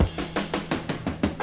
Chopped Fill 8.wav